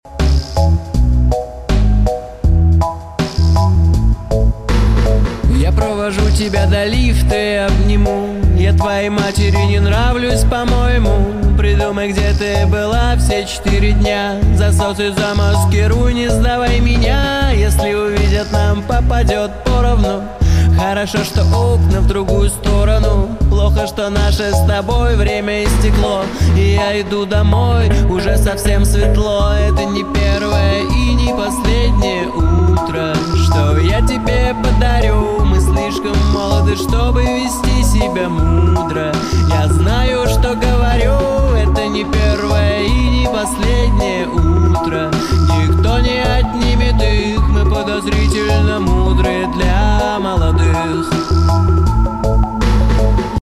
Unboxing new fuses for pyro sound effects free download